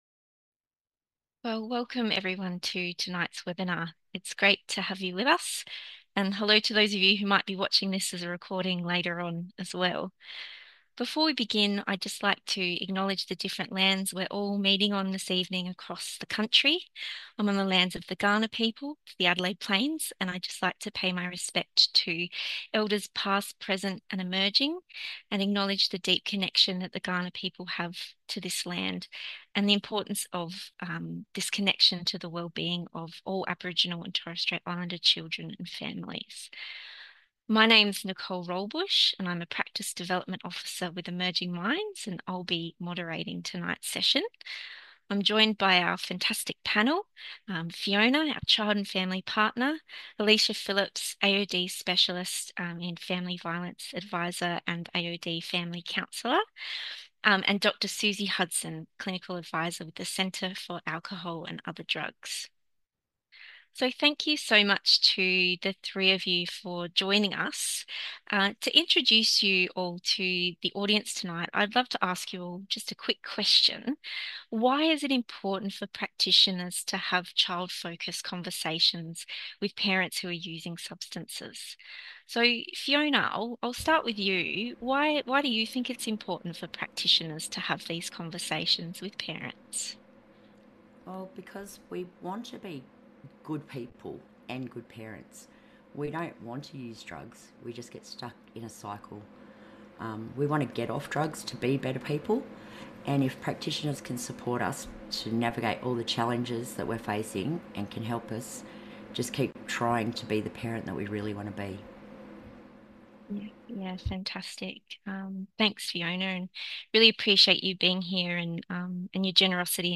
Watch this webinar to build your knowledge and develop practical skills for confident and effective child aware conversations with parents who use substances. It will outline strategies to encourage open, collaborative communication and support positive engagement.